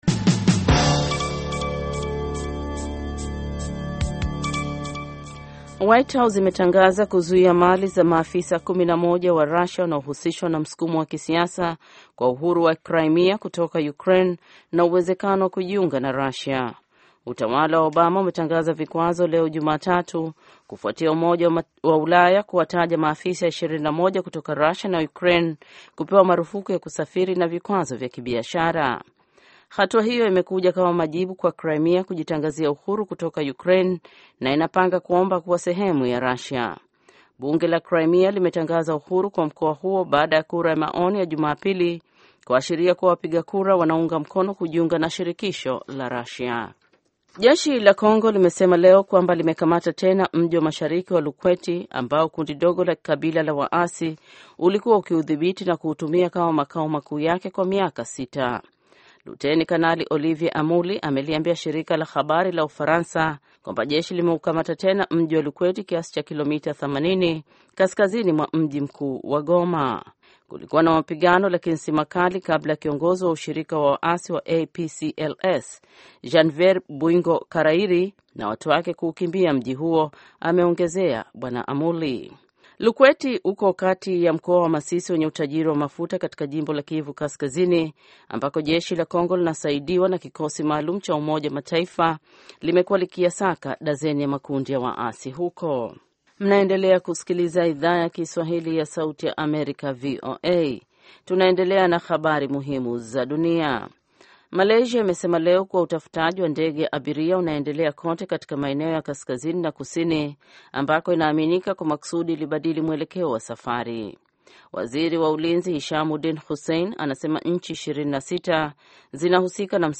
Taarifa ya Habari VOA Swahili - 6:20